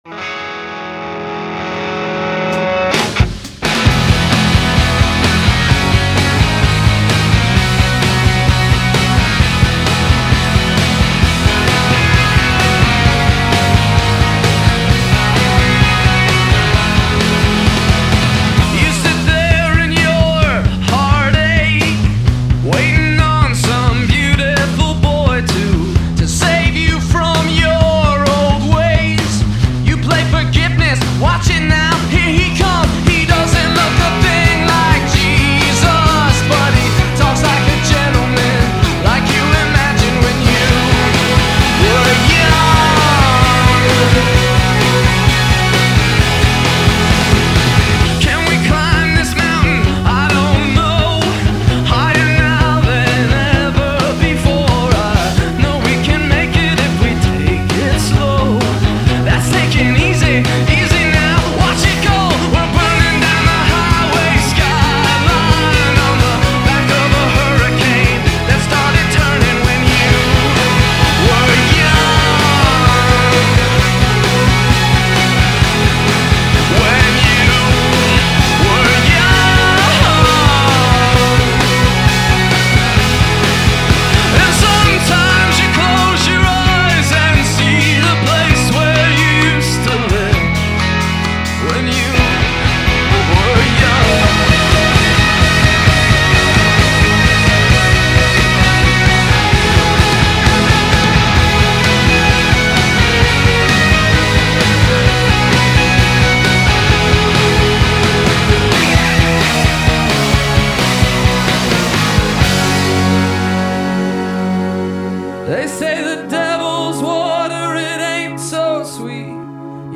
Рок Инди рок